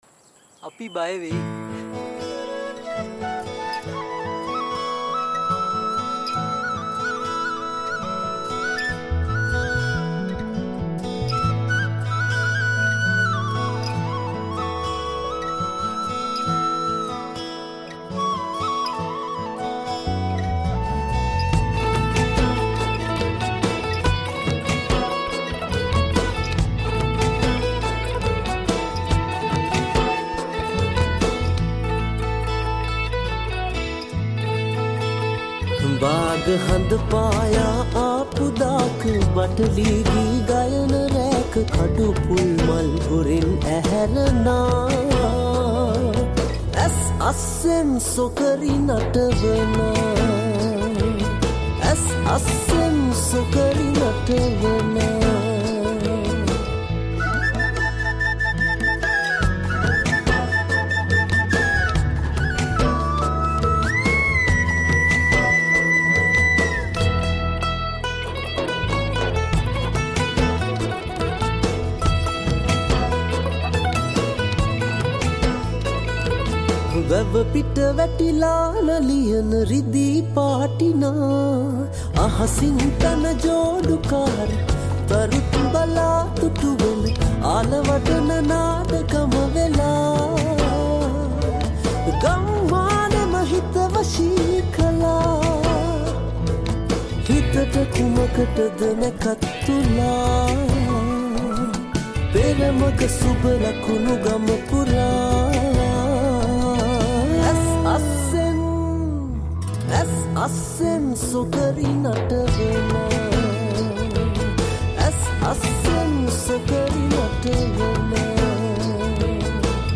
High quality Sri Lankan remix MP3 (3.5).
Remix